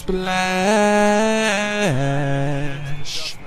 splashhh
Category: Sound FX   Right: Personal